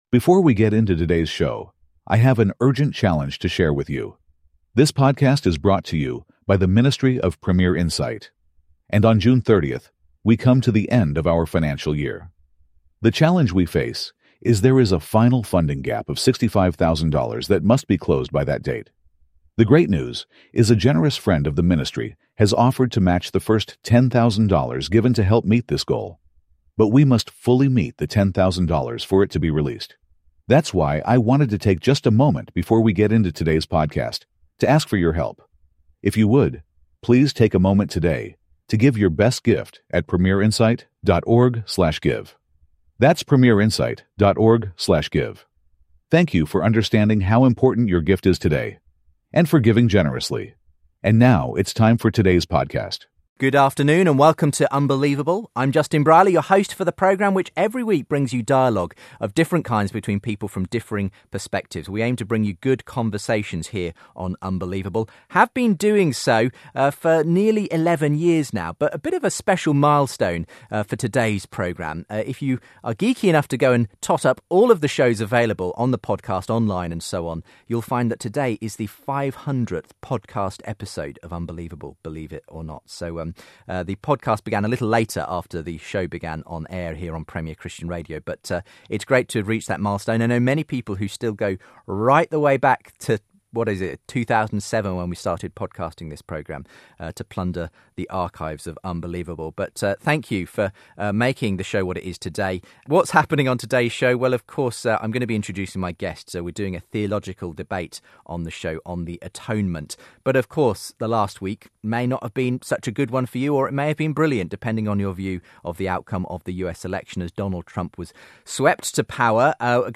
Christianity, Religion & Spirituality 4.6 • 2.3K Ratings 🗓 11 November 2016 ⏱ 82 minutes 🔗 Recording | iTunes | RSS 🧾 Download transcript Summary CORRECTED AUDIO!